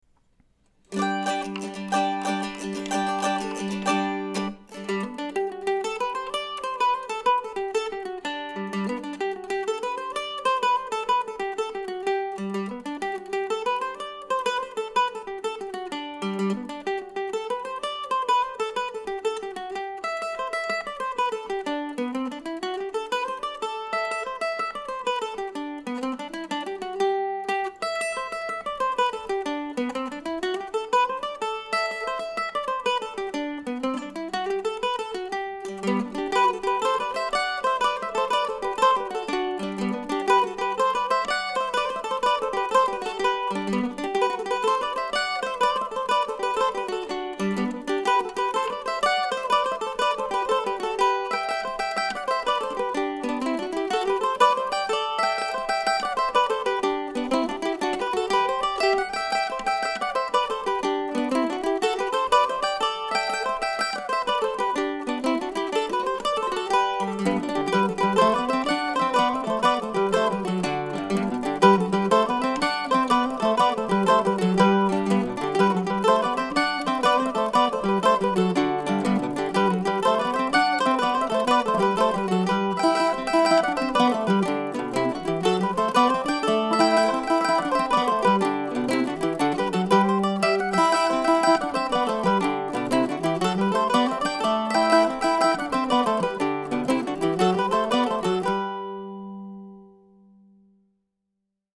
Today's tune is another in a series of jigs named, for lack of a more poetic title, after Tolley our aging "upstairs" cat. The recording was fun and easy to make and I was very pleased that the tune invited me to get out my old Flatiron octave mandolin (mandola, bouzouki, whatever you want to call it). You can hear it on the the bottom during the third pass through the tune.